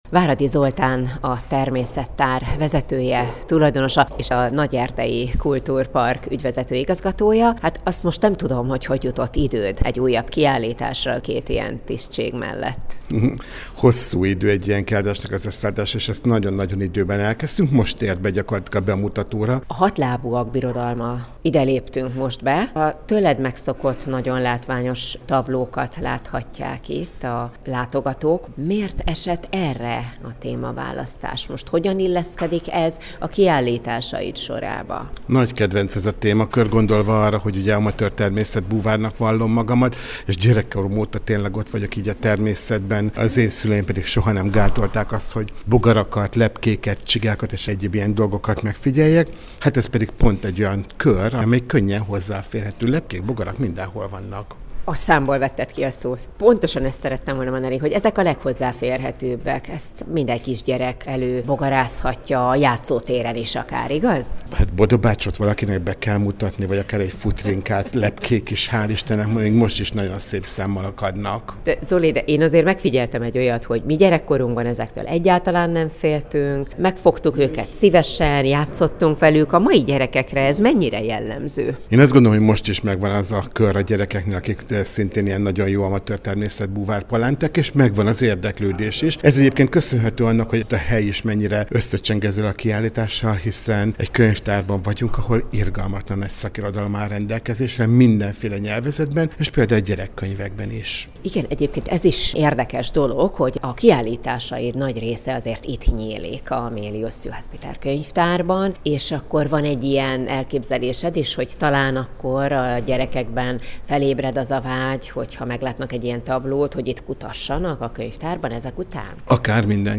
Meghallgatom az Eur�pa R�di� felv�tel�t a t�rlatvezet�sr�l!